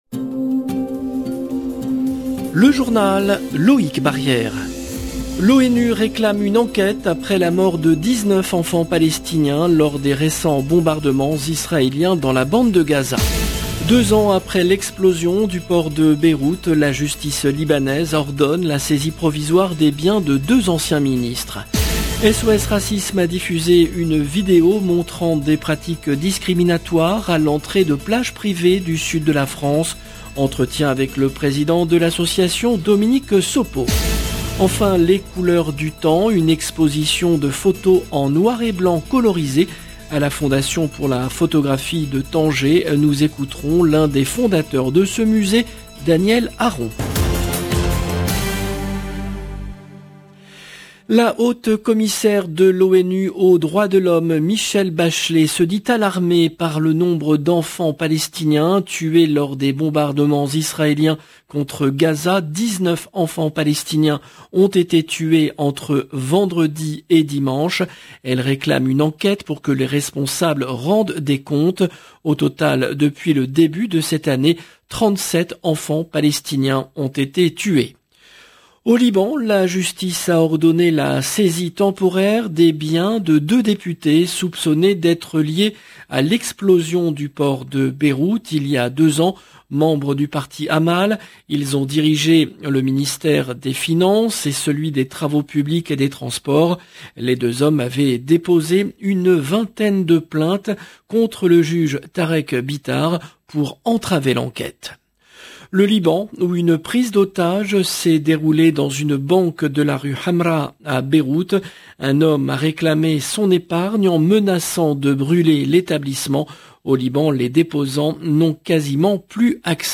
LE JOURNAL EN LANGUE FRANCAISE DU SOIR DU 11/08/22